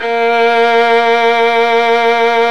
Index of /90_sSampleCDs/Roland L-CD702/VOL-1/STR_Violin 2&3vb/STR_Vln2 _ marc
STR  VL A#4.wav